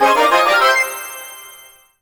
rank_up_01.wav